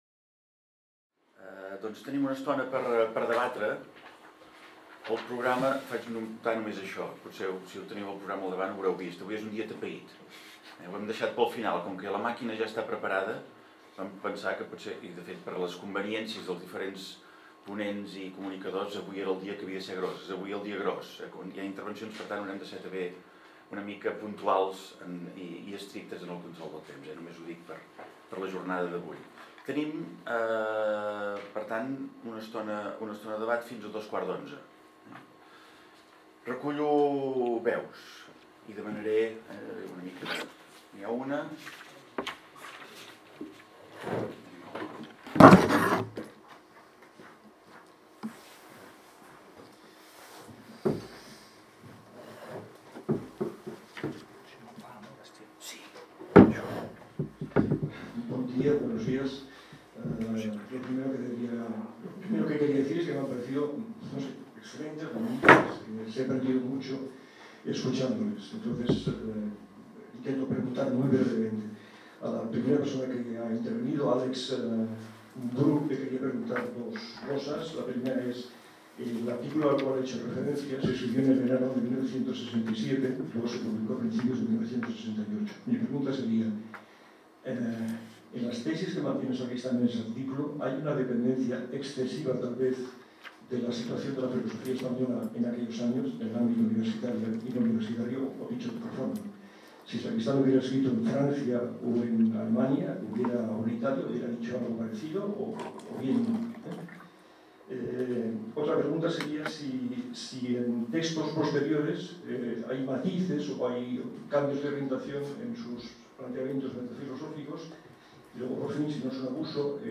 Debat sessió 7